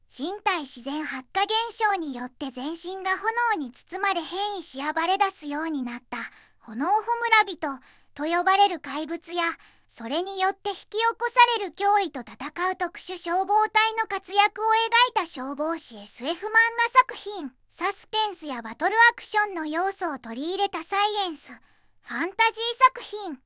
VOICEVOX: ずんだもんを利用しています